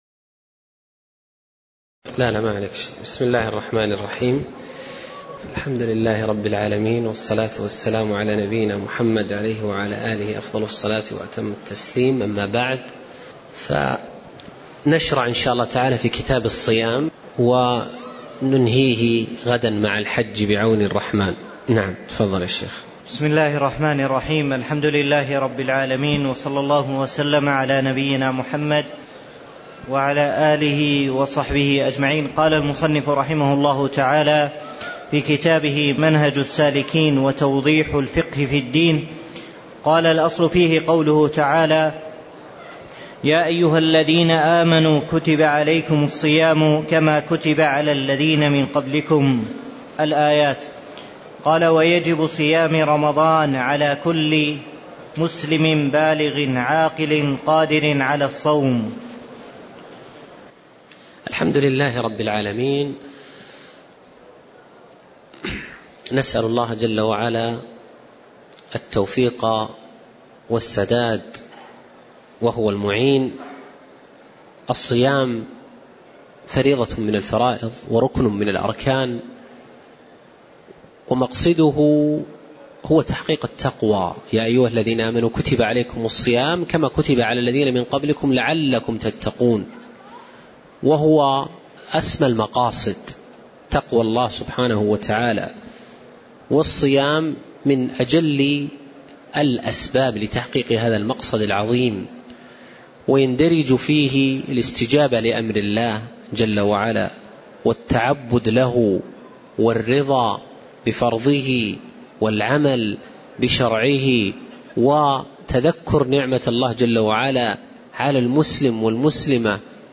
تاريخ النشر ١٧ شوال ١٤٣٧ هـ المكان: المسجد النبوي الشيخ